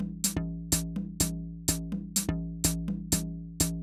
Drumloop 125bpm 06-B.wav